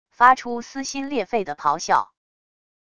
发出撕心裂肺的咆哮wav音频